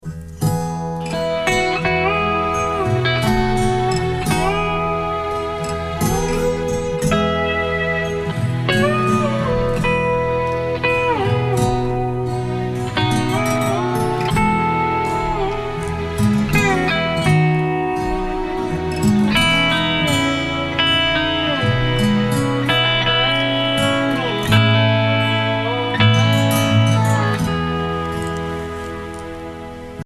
ATTACHMENT07 Audio example 5 lap steel solo 591 KB MP3 Audio File (Tagged)